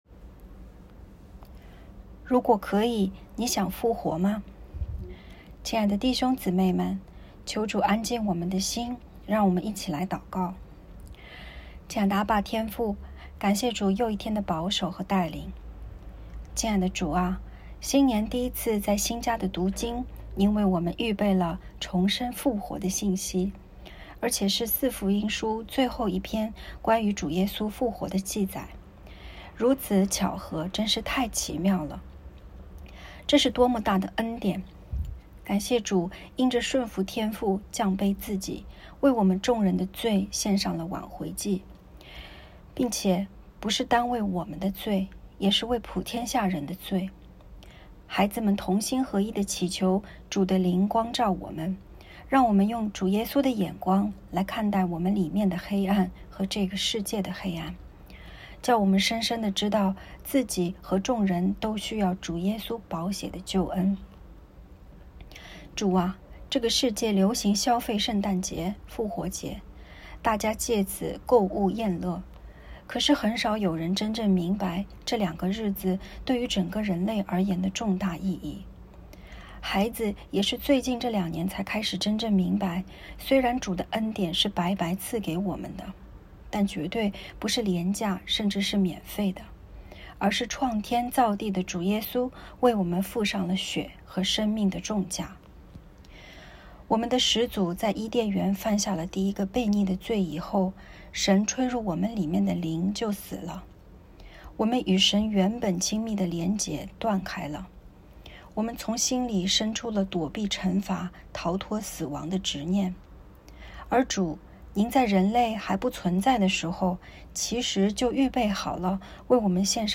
✨晚祷时间✨1月17日（周一）